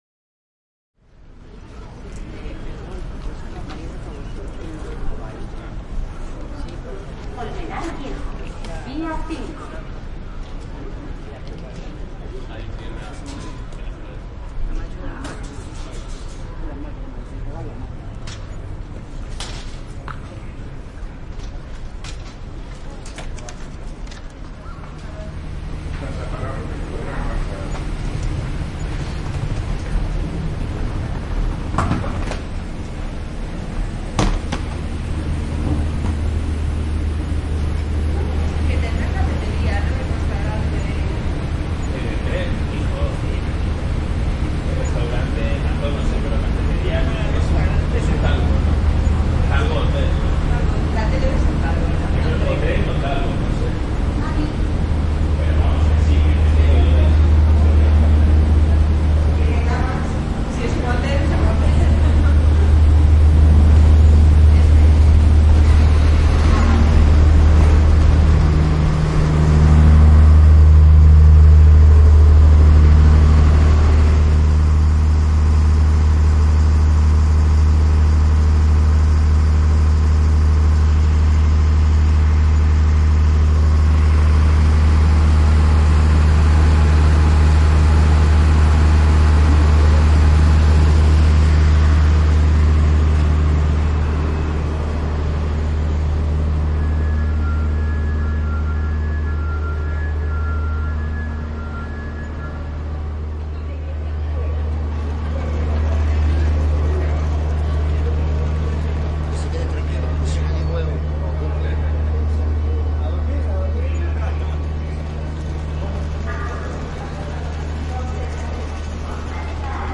德国柏林Priesterweg的火车经过 " 货物列车经过 - 声音 - 淘声网 - 免费音效素材资源|视频游戏配乐下载
通过aprox 15m距离的货物火车。 2016年9月在柏林Priesterweg以Zoom HD2录制成90°XY